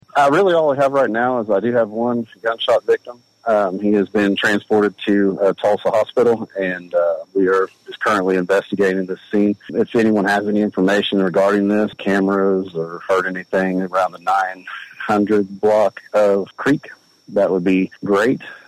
Dewey Police Chief Jimmy Gray said,